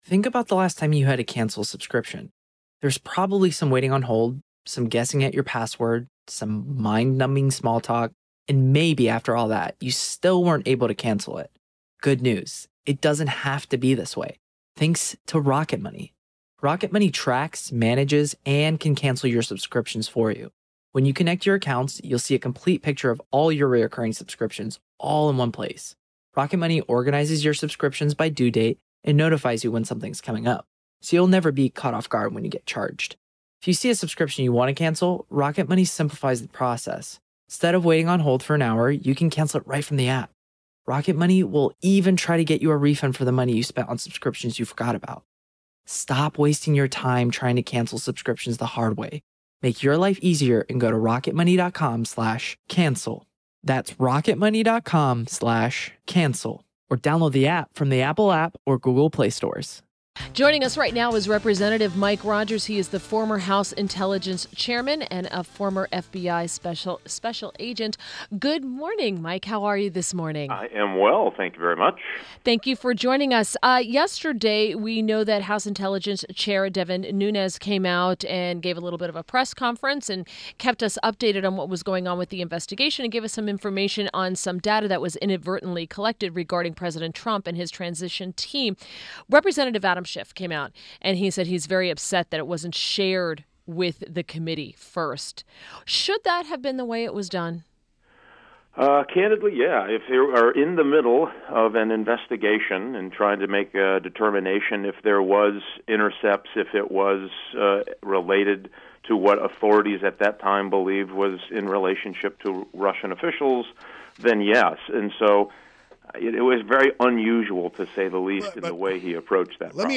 WMAL Interview - REP MIKE ROGERS - 03.23.17